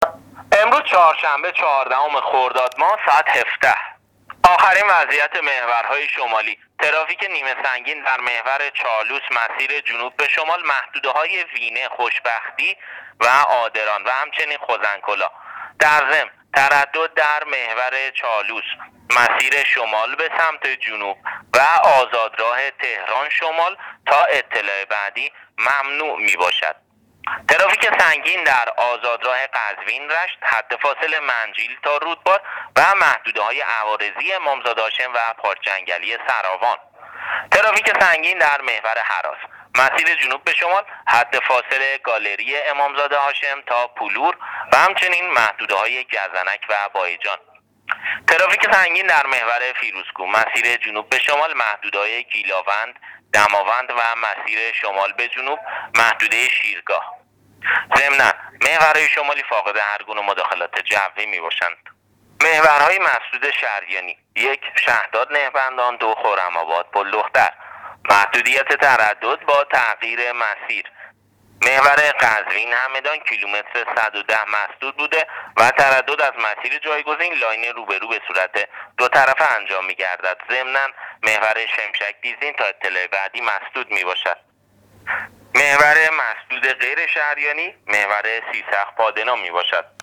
گزارش رادیو اینترنتی از وضعیت ترافیکی جاده‌ها تا ساعت ۱۷ چهاردهم خردادماه